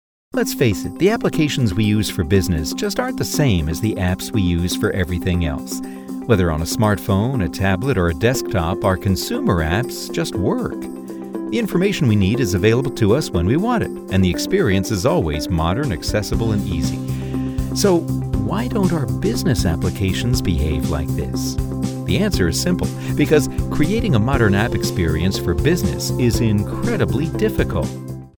Explainer Demo
English - USA and Canada
Middle Aged